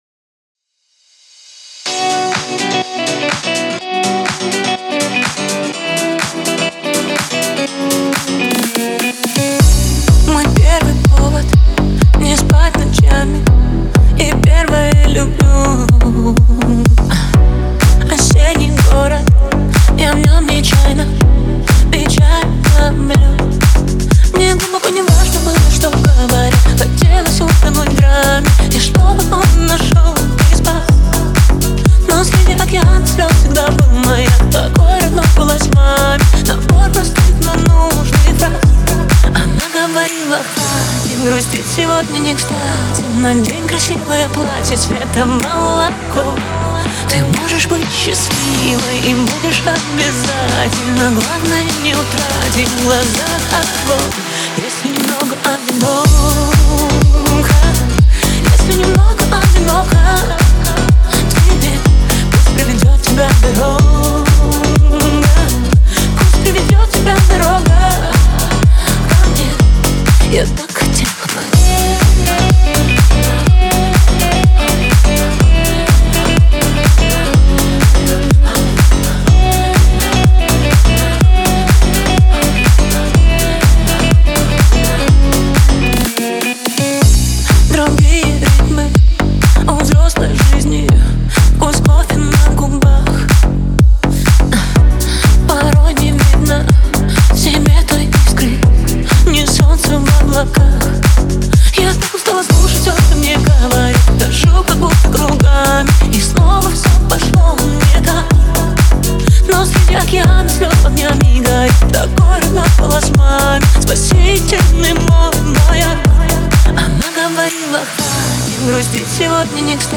запоминающийся мелодичный вокал